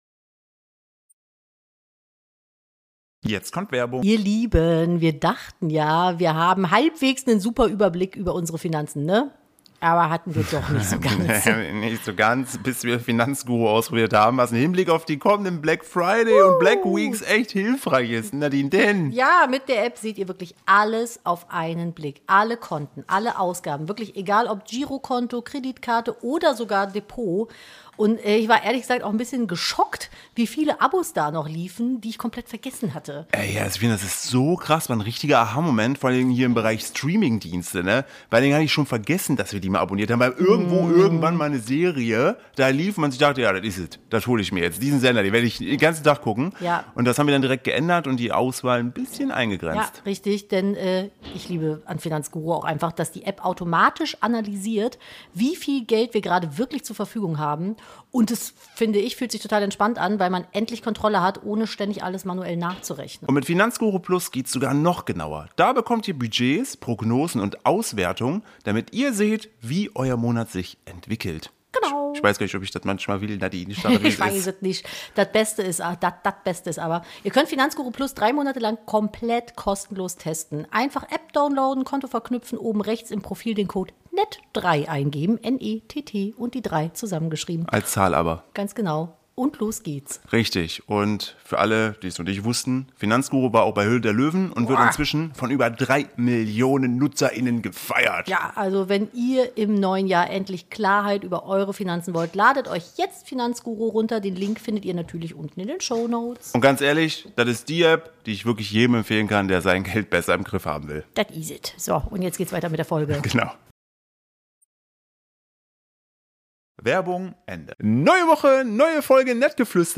#251: Brandgansherd ~ Nettgeflüster - Der Podcast eines Ehepaars Podcast